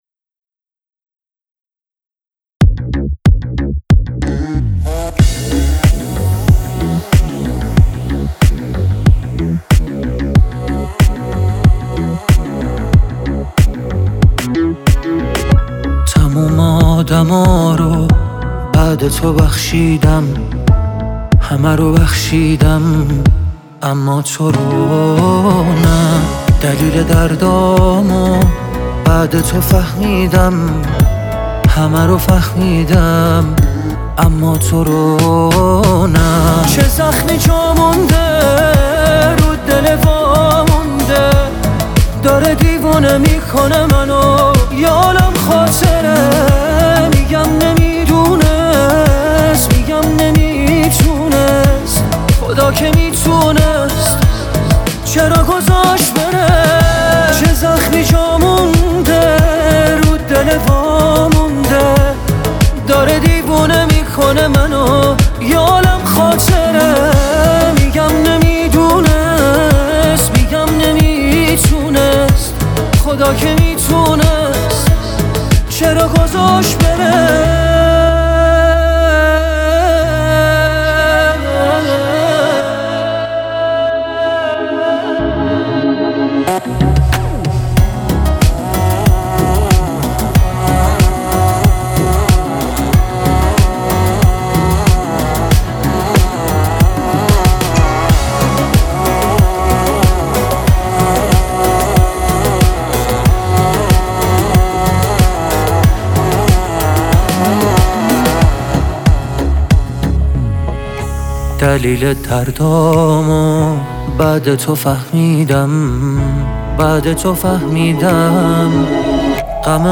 خوانندگان پاپ ایران